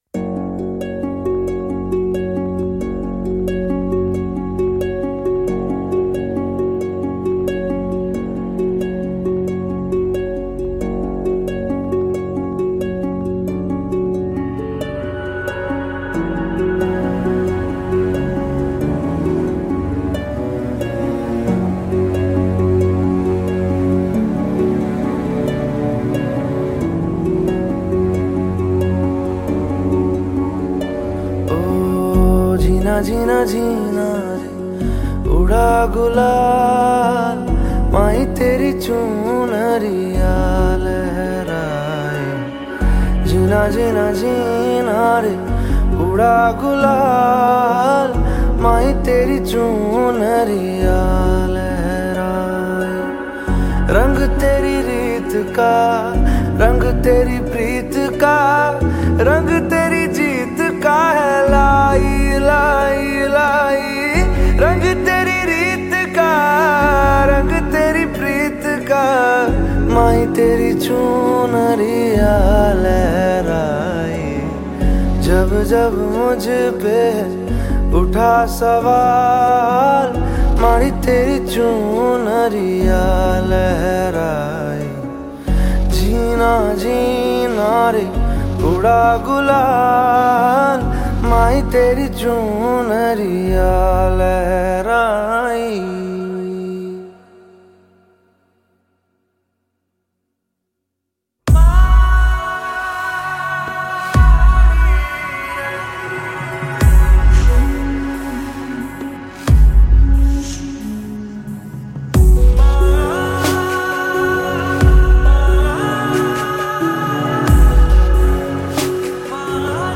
Bollywood track